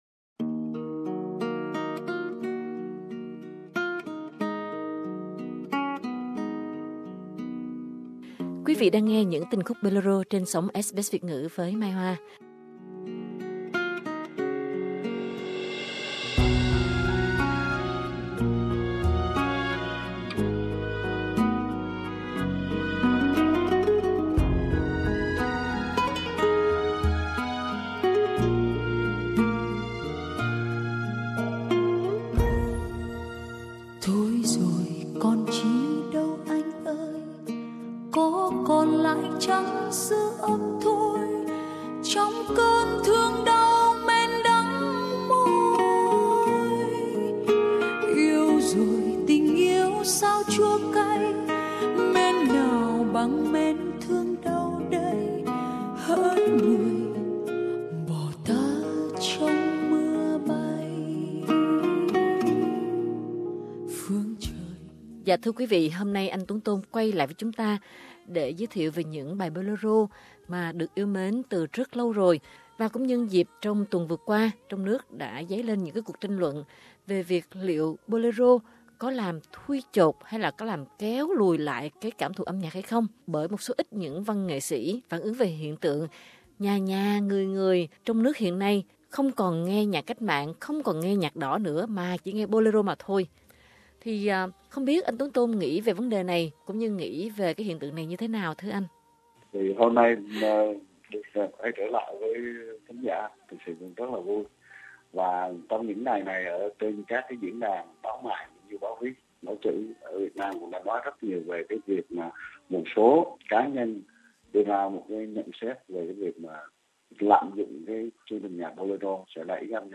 Nghe các bài Bolero được sáng tác bởi các nhạc sĩ tài dành xuất thân Bắc Kỳ chính hiệu con nai vàng, và được trình bày bởi các ca sĩ Bắc rặt ròi không pha trộn, nhưng đã yêu rồi thì chỉ có ôm Bolero vào lòng mà thôi!